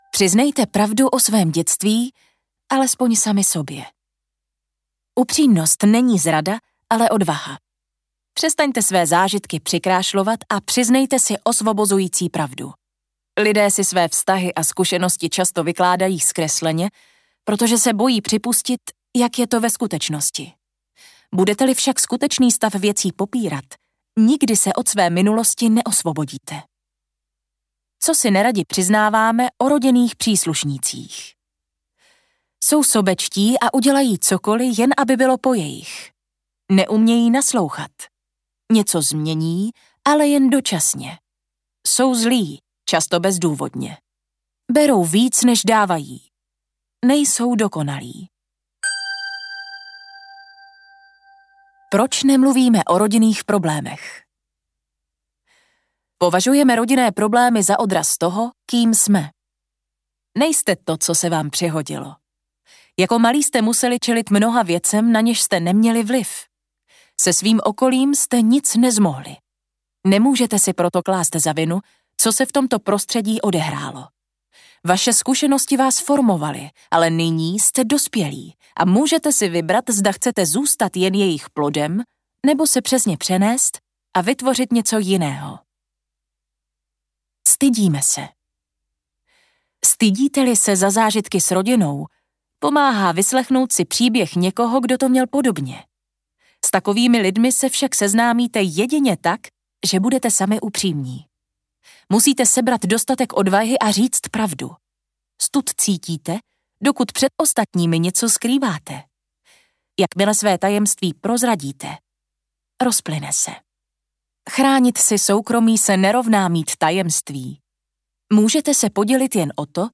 Audiokniha Už žádne drama - Nedra Glover Tawwab | ProgresGuru